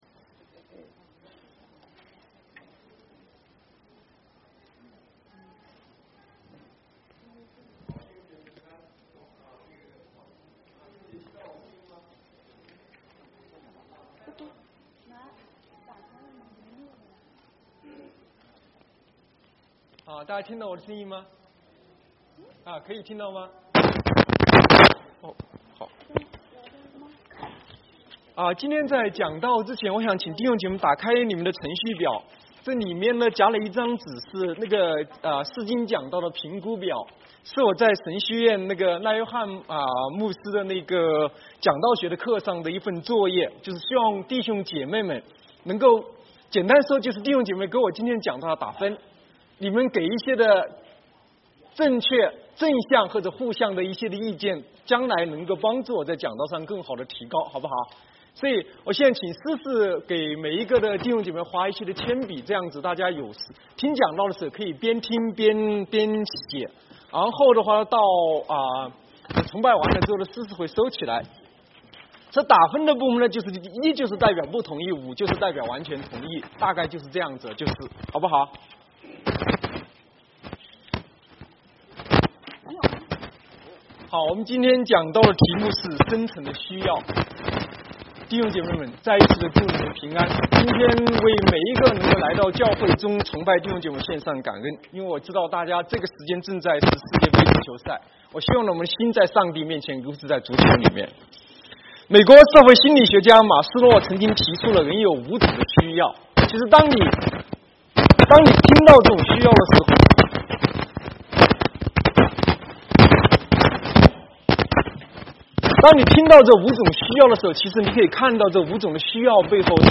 Sermons | South Gate Alliance Church | Mandarin (國語)